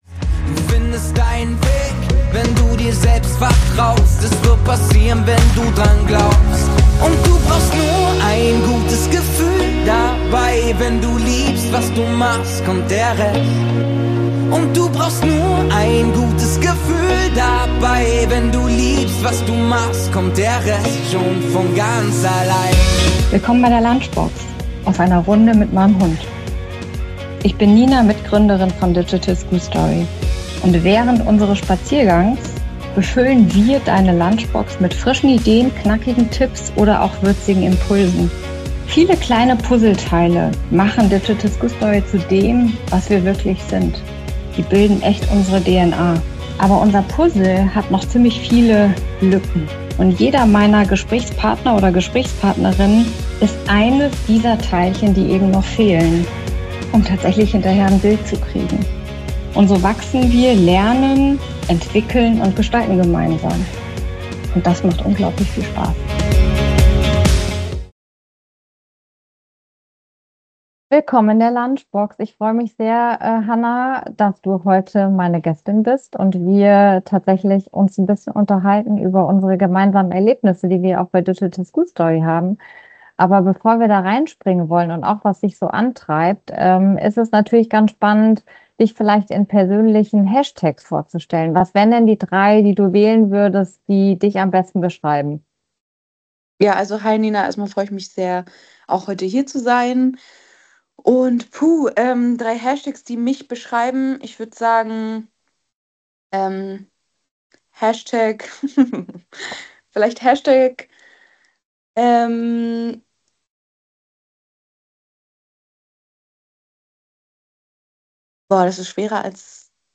Ein ehrliches, reflektiertes und inspirierendes Gespräch über Vorbilder, Rollenbilder und darüber, warum Schule dringend neue Räume braucht.